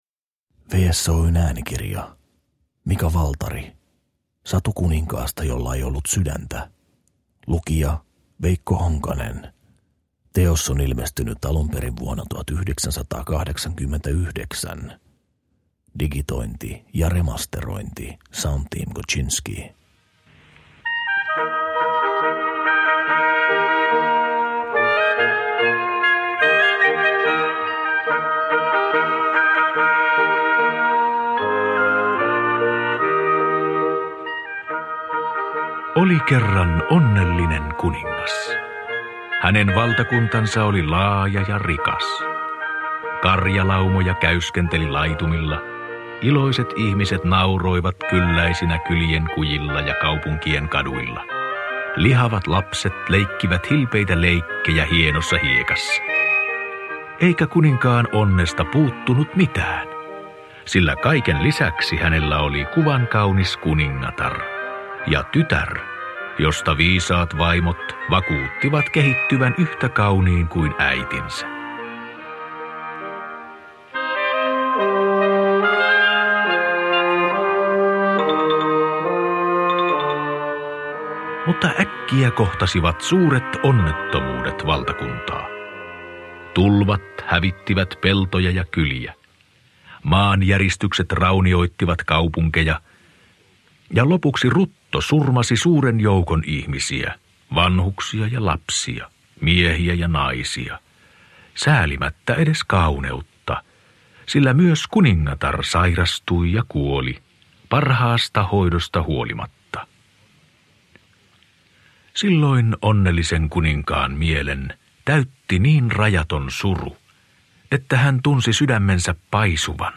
Satu kuninkaasta jolla ei ollut sydäntä – Ljudbok – Laddas ner